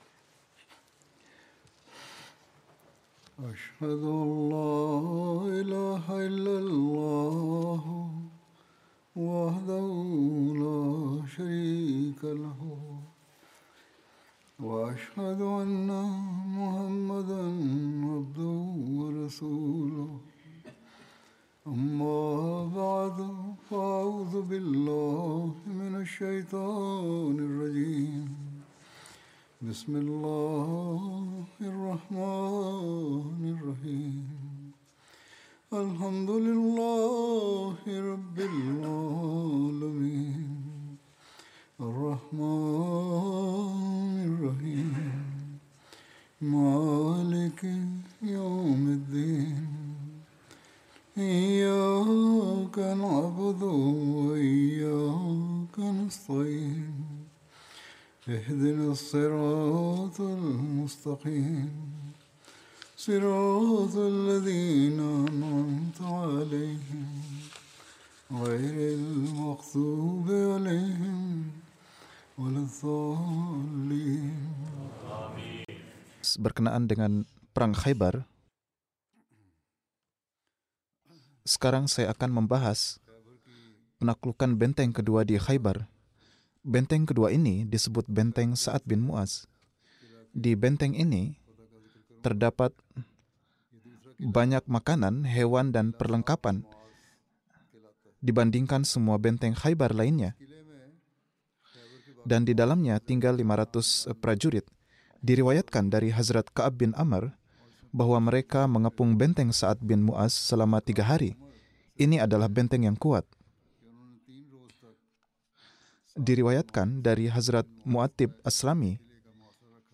Indonesian translation of Friday Sermon delivered by Khalifa-tul-Masih on July 5th, 2024 (audio)